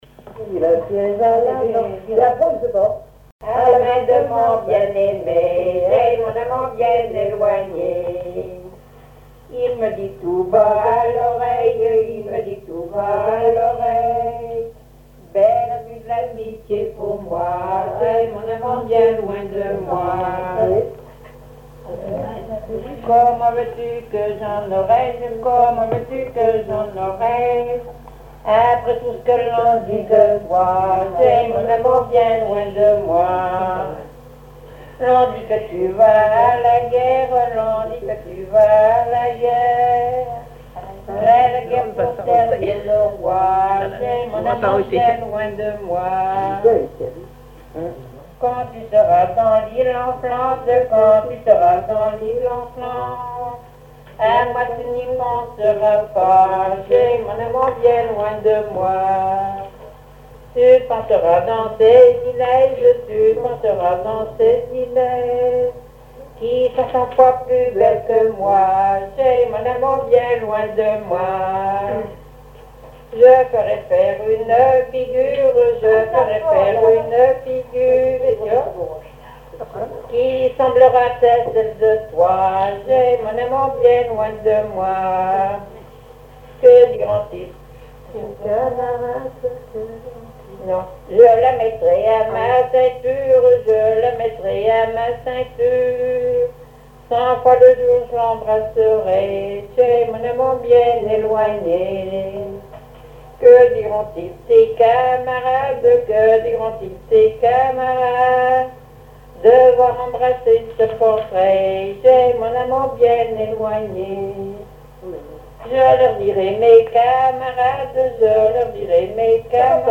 danse : ronde à la mode de l'Epine
chansons traditionnelles et commentaires
Pièce musicale inédite